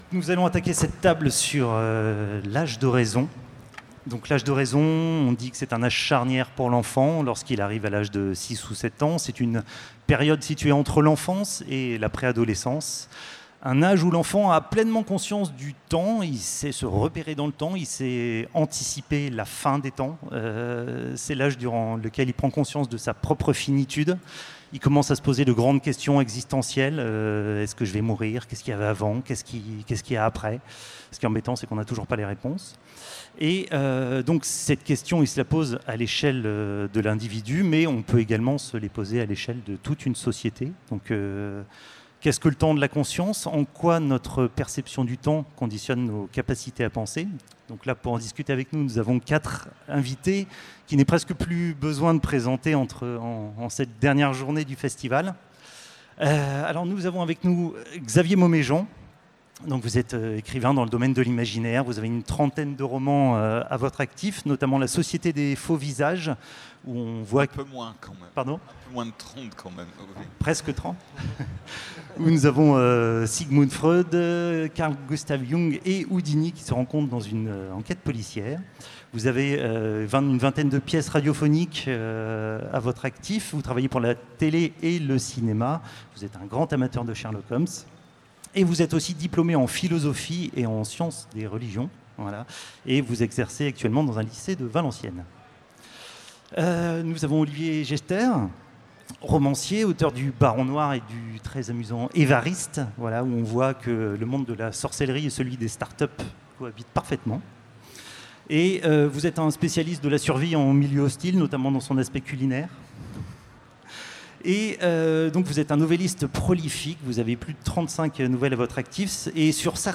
Utopiales 2017 : Conférence L’âge de raison